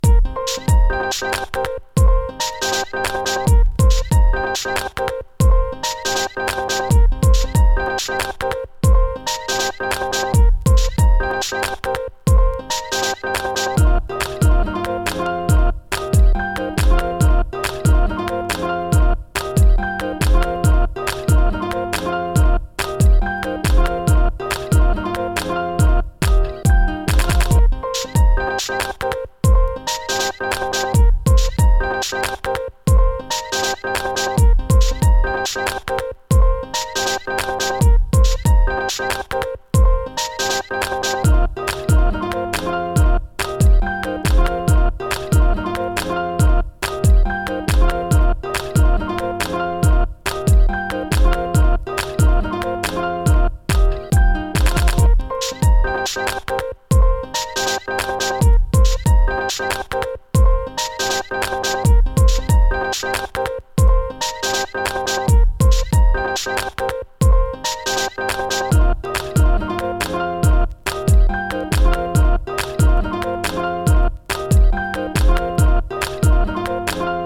メローなネタ使いの